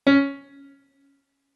MIDI-Synthesizer/Project/Piano/41.ogg at 51c16a17ac42a0203ee77c8c68e83996ce3f6132